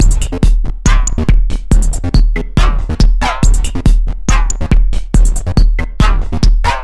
Tag: 140 bpm Techno Loops Groove Loops 1.15 MB wav Key : Unknown